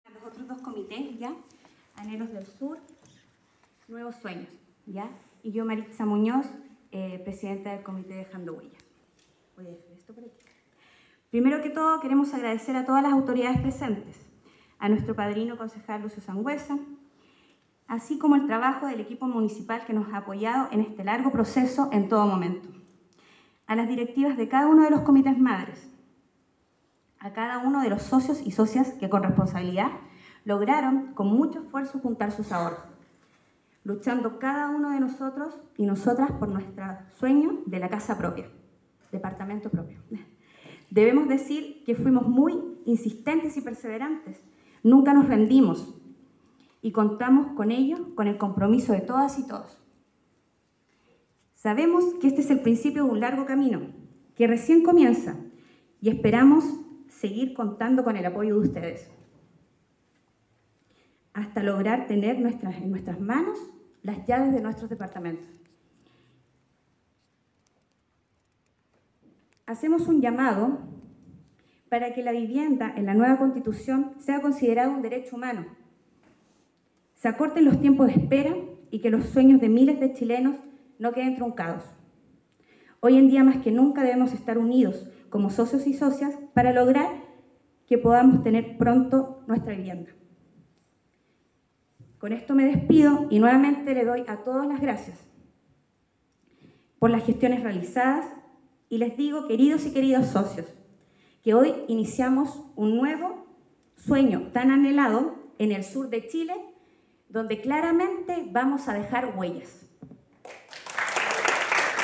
La actividad que se desarrolló en el Teatro Lord Cochrane contó con la presencia del Delegado Presidencial, Cesar Asenjo; el senador, Alfonso de Urresti; la senadora electa, María José Gatica; la Gobernadora(s), Paz de La Maza; el Seremi de Vivienda y Urbanismo, Ignacio Vidal; la Alcaldesa de Valdivia, Carla Amtmann; junto a las dirigentes y beneficiarios.